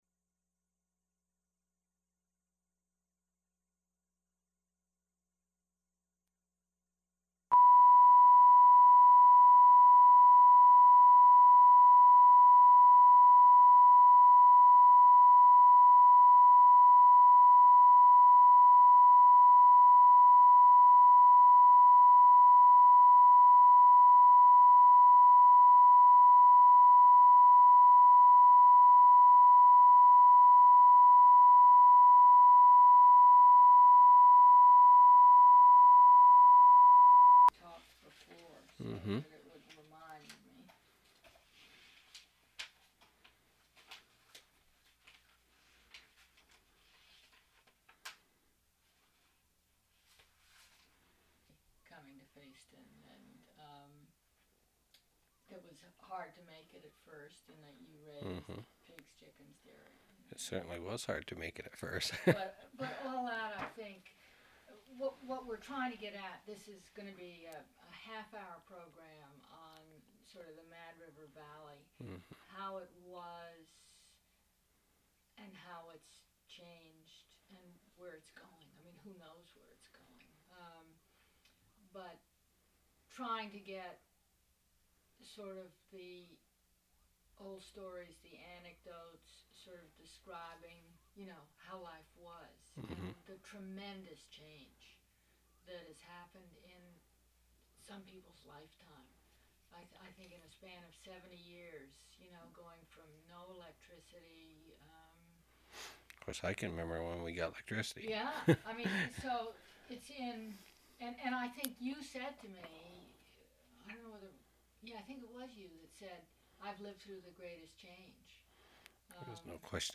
Interview
sound cassette (DAT)